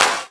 Snare drum alternatives Free sound effects and audio clips
• Good Clap Single Hit D Key 04.wav
Royality free clap - kick tuned to the D note.
good-clap-single-hit-d-key-04-QWS.wav